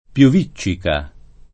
pioviccica [ p L ov &©© ika ]